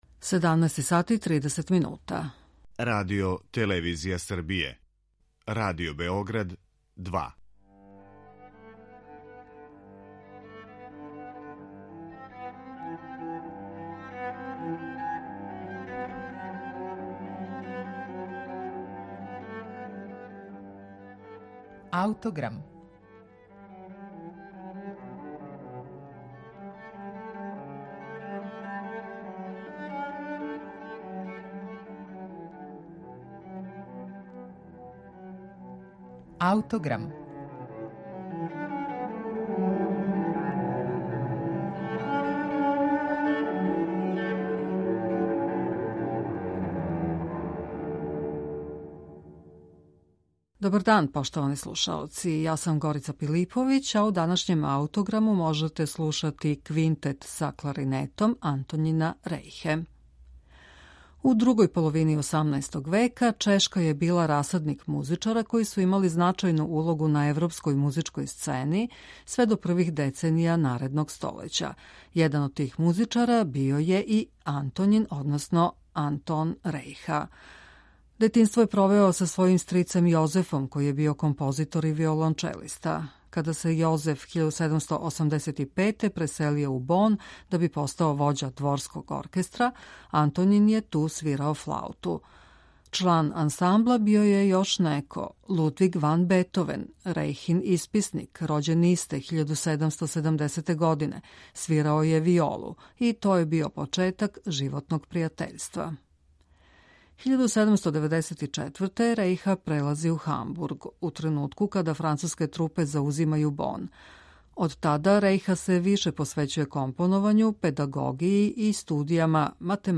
Квинтет за кларинет и гудаче